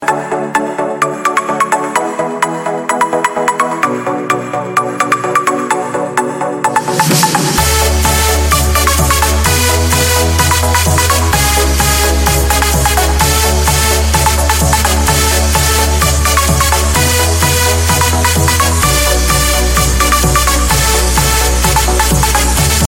Hip-Hop Ringtones